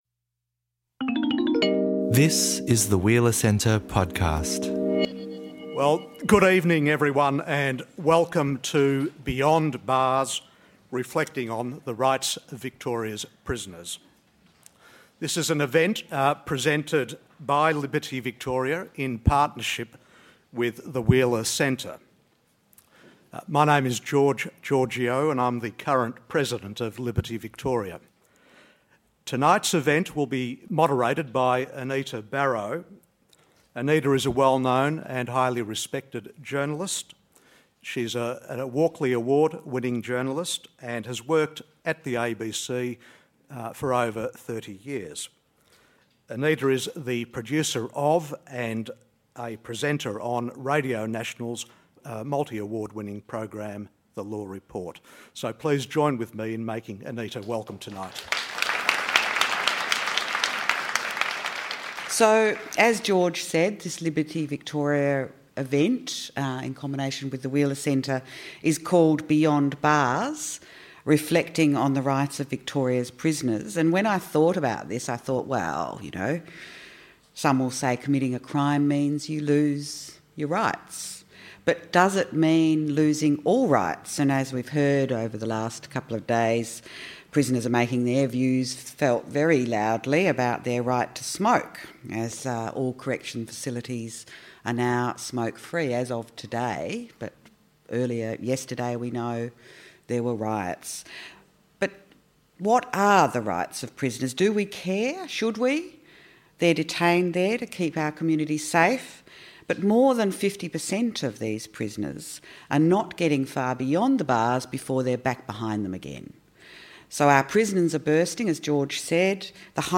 In this discussion – recorded the day after riots broke out at the Metropolitan Remand Centre at Ravenhall in outer Melbourne – our guests focus on how we might strike a balance between community safety and the protection of human rights of prisoners. We’ll look at what restrictions are placed on prisoners’ rights, examine what’s happening in other jurisdictions and ask: what does rehabilitation look like in 2015, and who benefits from increasing prison population numbers, anyway?